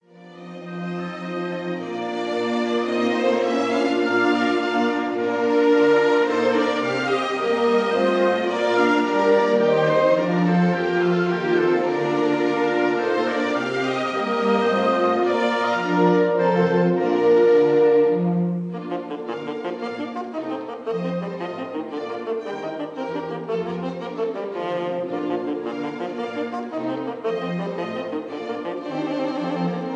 Rondo: tempo di menuetto